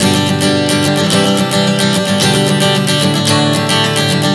Prog 110 G-C-G-D.wav